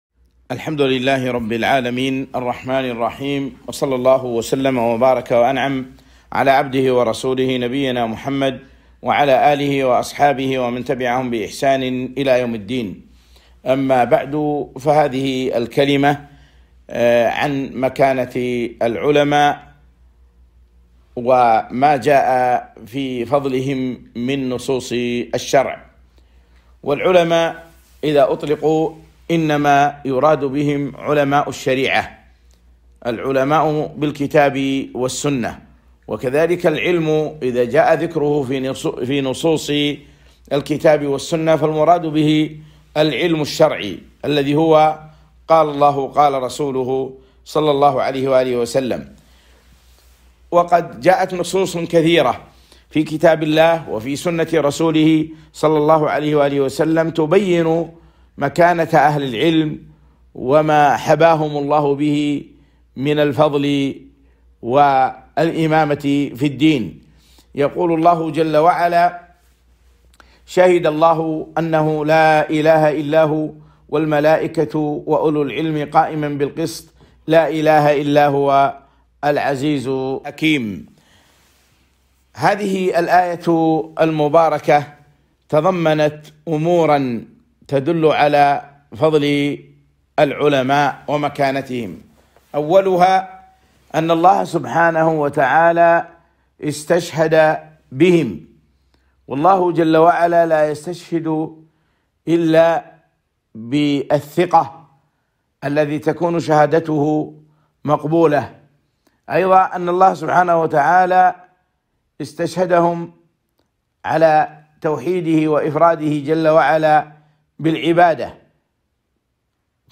محاضرة - مكانة العلماء وأهل العلم